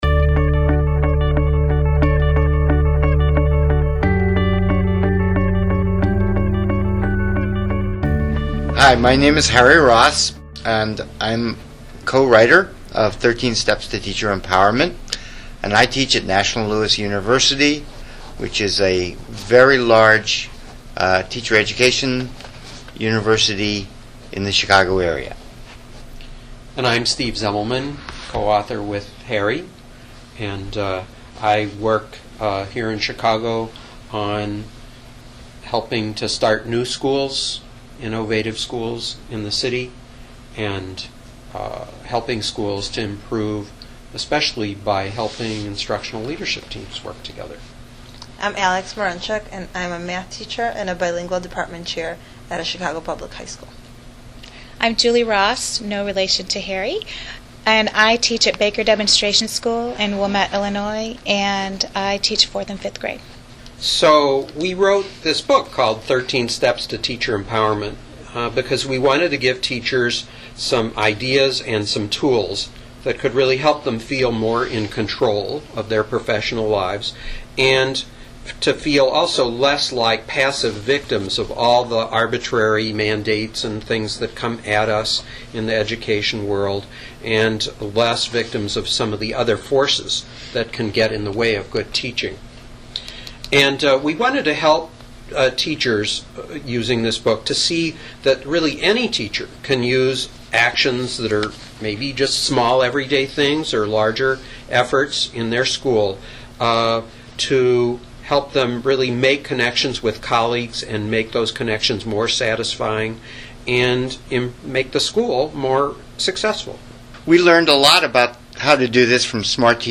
interview two teachers who got the teaching life they wanted with help from the 13 Steps.